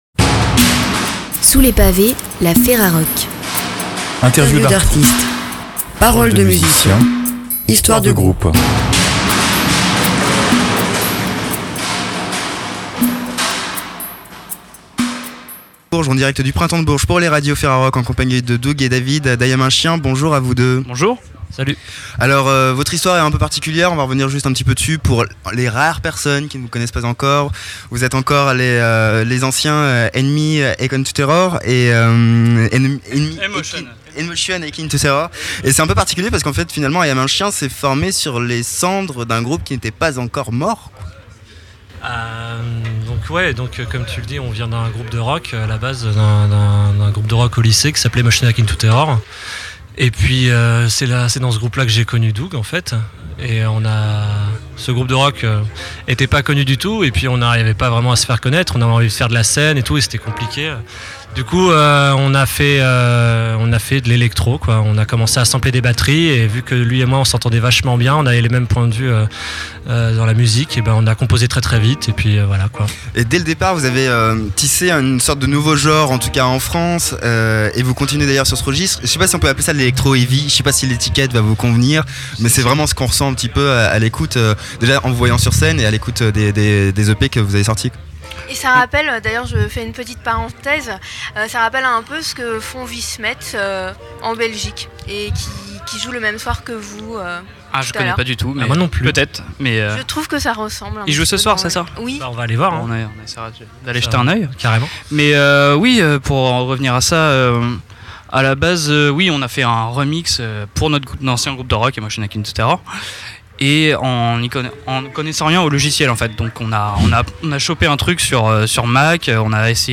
Vous êtes l'artiste ou le groupe interviewé et vous souhaitez le retrait de cet interview ? Cliquez ici.
"Sous les pavés, la FERAROCK" est un projet de numérisation des archives sonores de la FERAROCK, dans le cadre de l'appel à projets 2011 du Ministère de la Culture.
itw_i_am_un_chien_2011.mp3